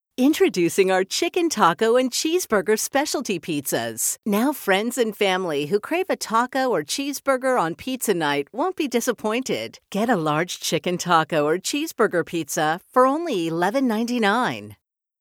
Choose from one of our professionally produced, pre-recorded studio quality messages or customize for your store!
Food Specials Auto-Attendant Messaging